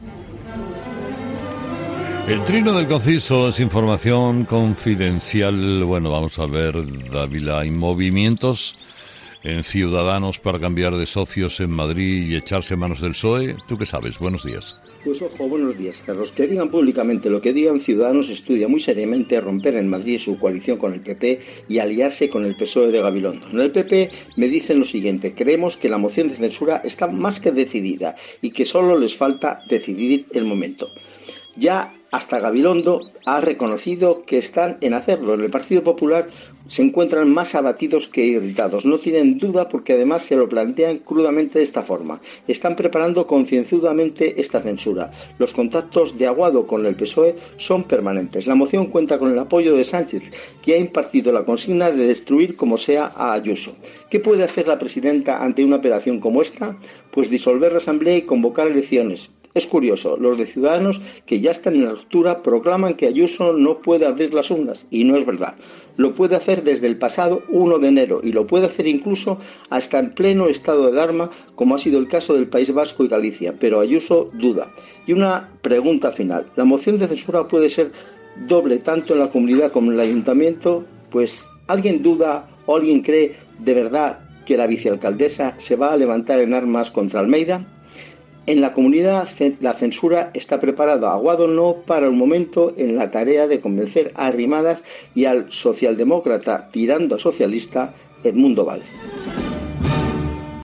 Entrevistado: "Carlos Dávila"